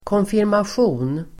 Ladda ner uttalet
konfirmation.mp3